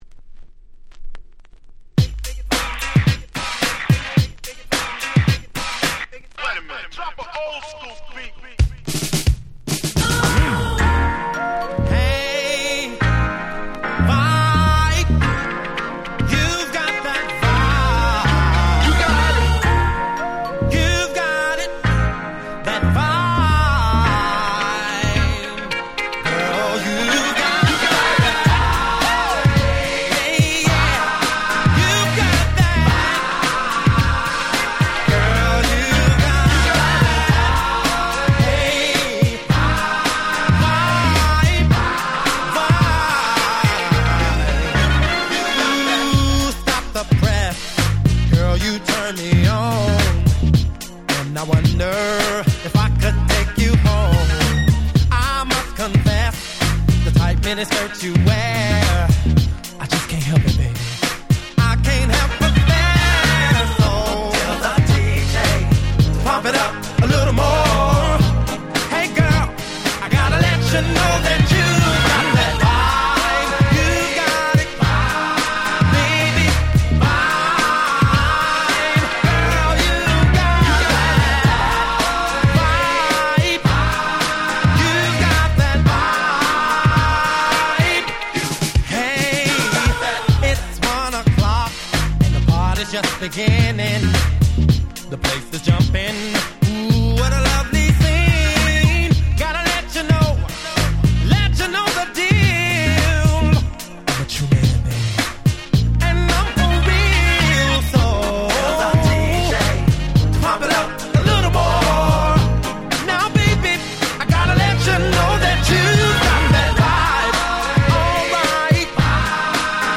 R&B